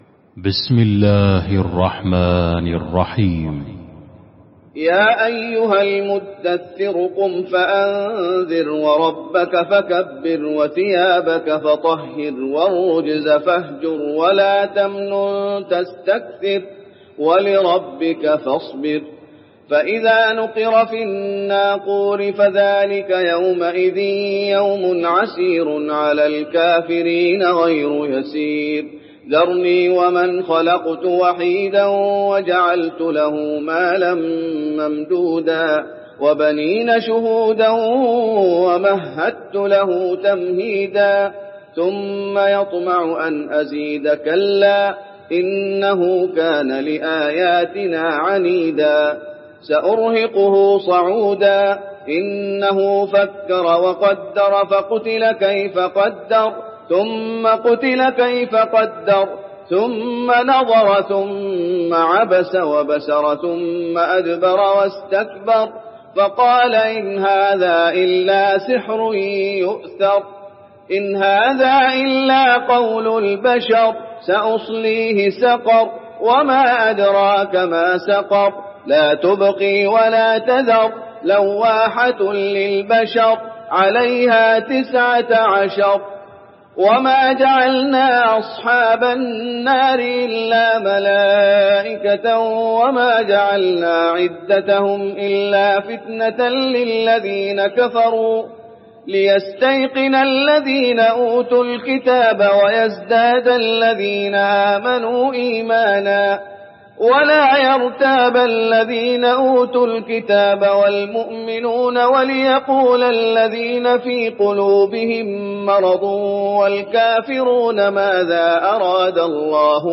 المكان: المسجد النبوي المدثر